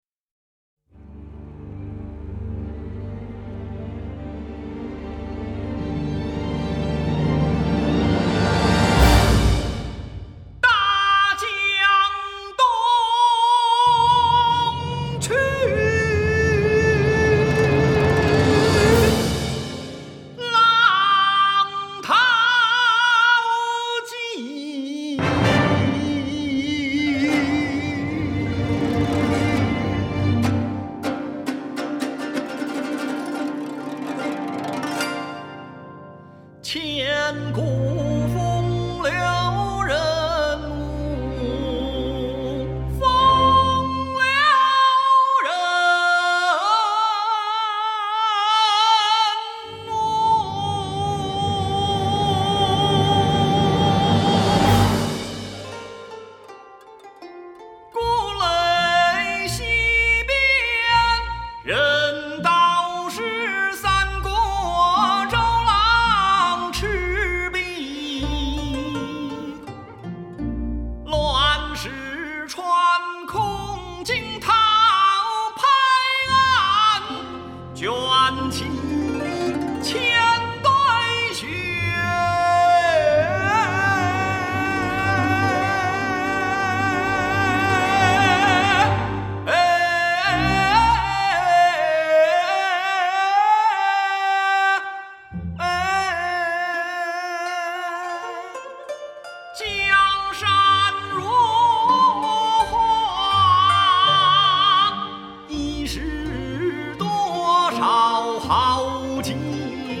★ 音效穿雲裂石，鼓點直擊心房，國樂史無前例的磅礡感動。
在全亞洲最大、設備最先進的中央電視臺 480 平方米錄音棚錄製，音質音色絕佳，音場寬深、定位精準
弦樂恢宏洶湧，群鼓響動古今，千古一歎凜然驚世。